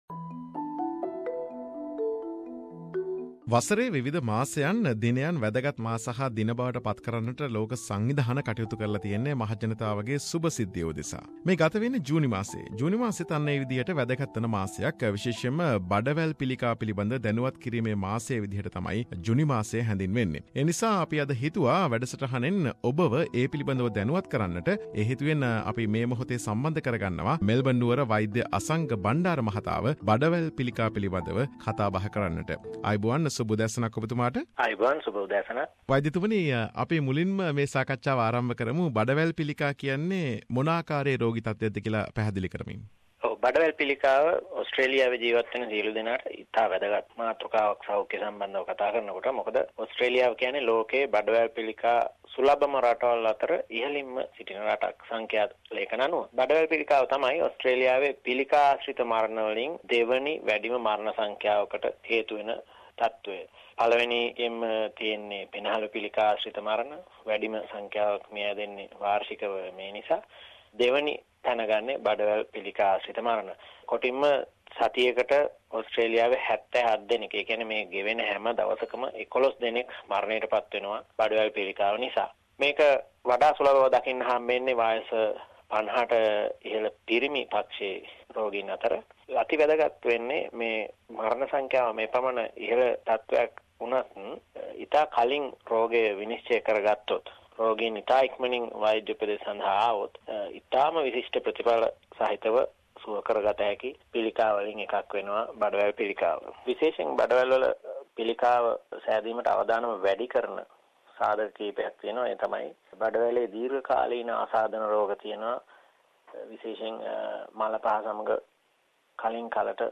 June is the month of Bowel Cancer awareness. This medical interview focused on Bowel Cancer